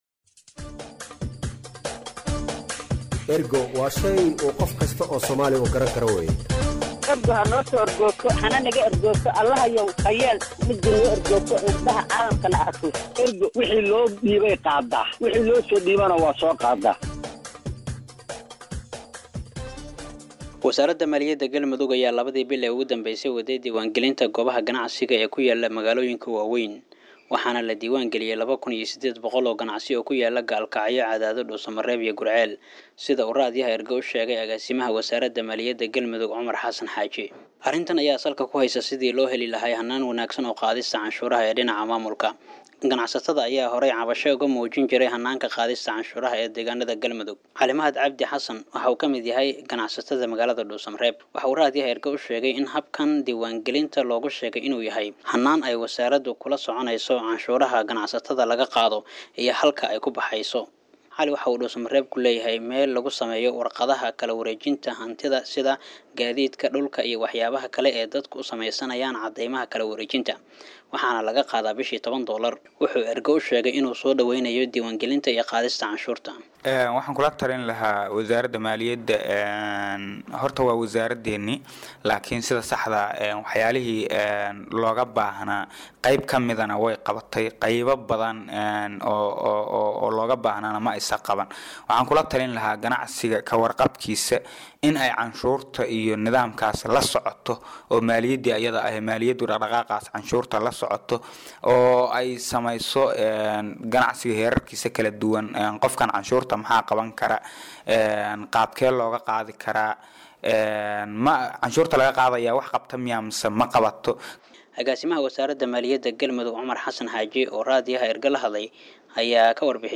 warbixinta-ganaxsiga-la-diiwaangeliyay.mp3